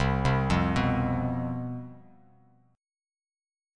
CM7sus4 chord